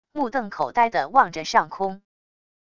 目瞪口呆的望着上空wav音频生成系统WAV Audio Player